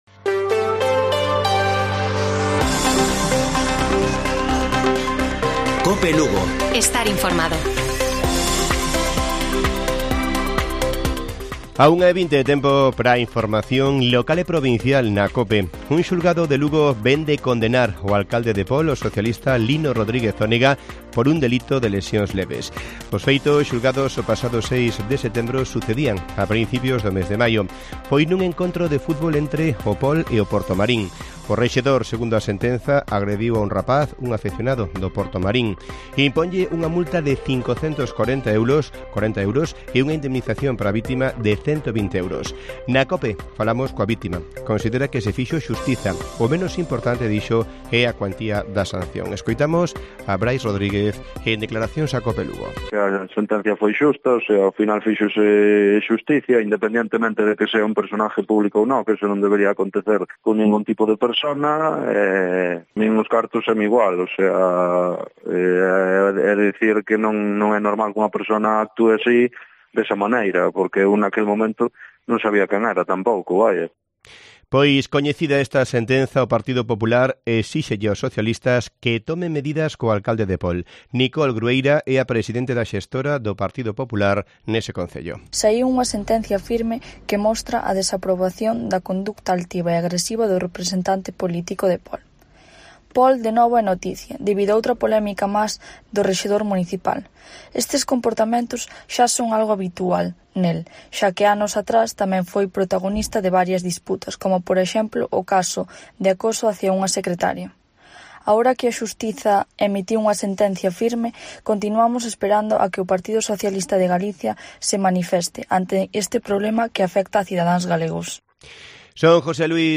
Informativo Mediodía de Cope Lugo. 14 de outubro. 13:20 horas